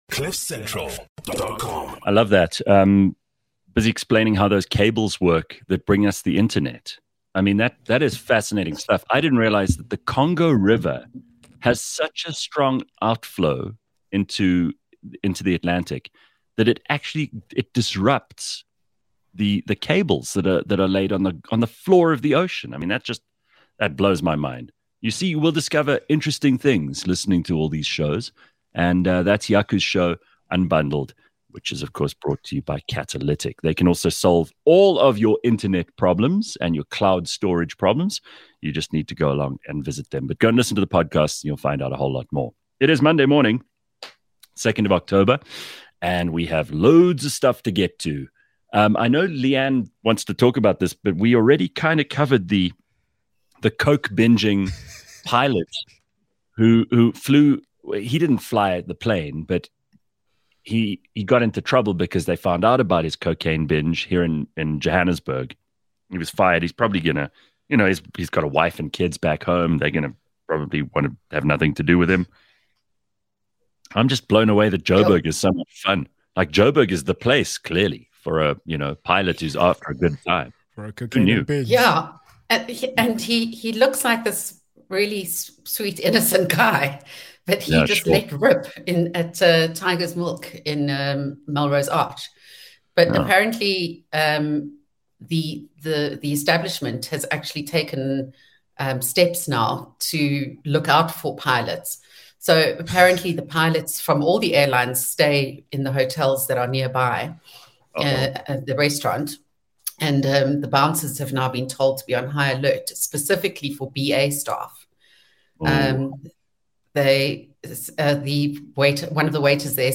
A live podcast show, that’s like a morning radio show, just much better.